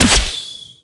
bow_fire_01.ogg